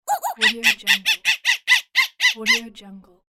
Cartoon Angry Monkey Sound Button - Free Download & Play